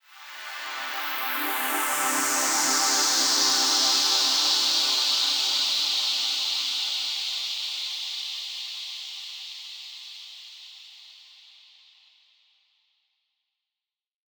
SaS_HiFilterPad01-C.wav